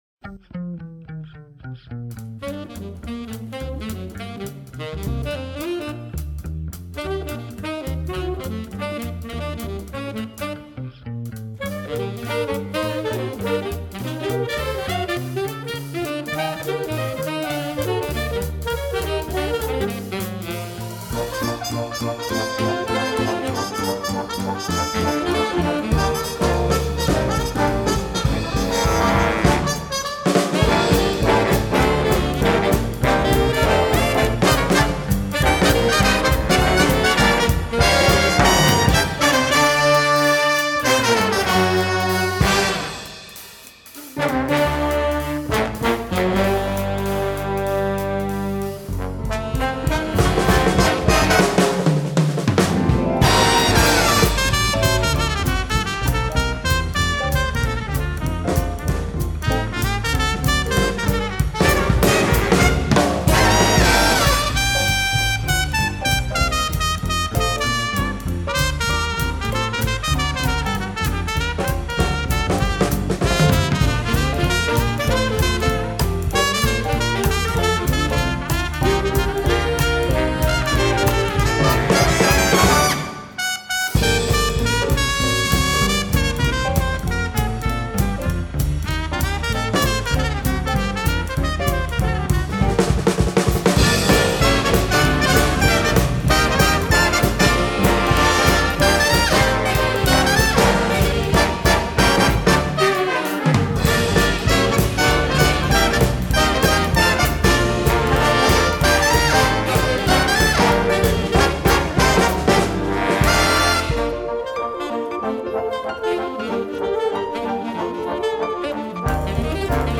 Répertoire pour Harmonie/fanfare - Big Band